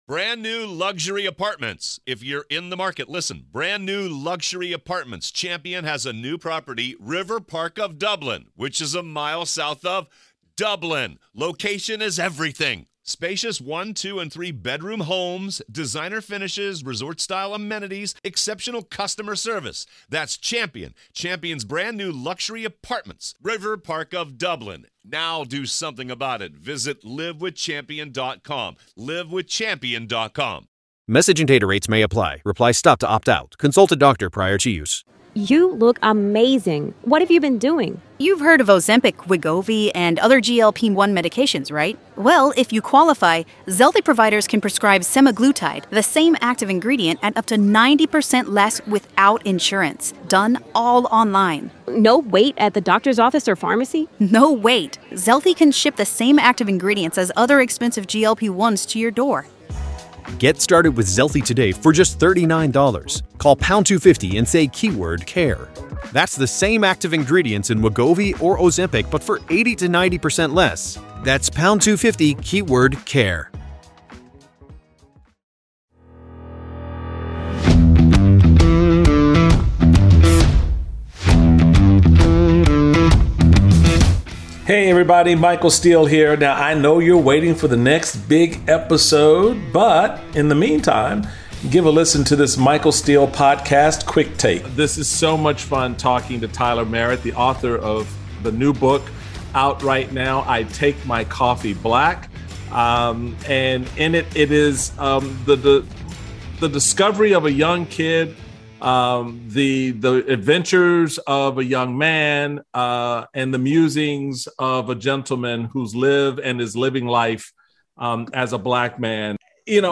In part 1 of this interview